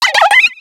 Cri de Carapagos dans Pokémon X et Y.